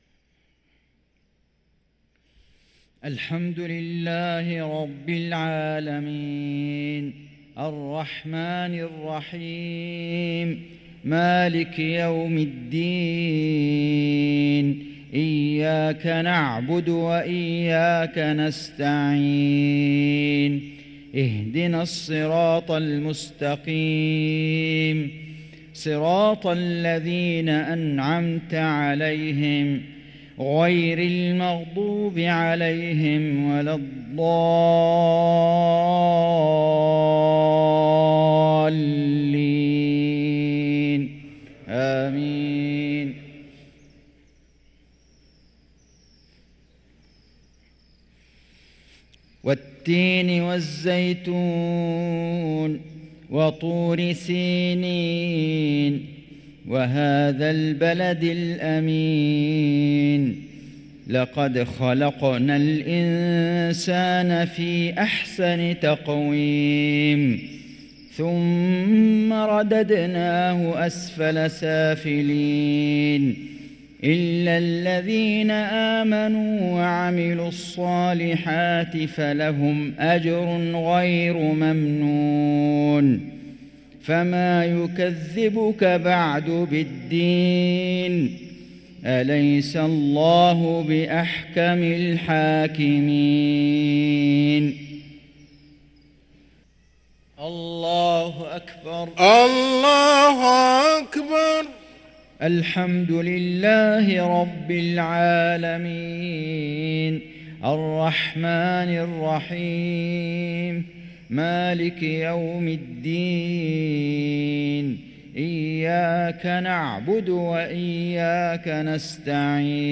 صلاة المغرب للقارئ فيصل غزاوي 2 شوال 1444 هـ
تِلَاوَات الْحَرَمَيْن .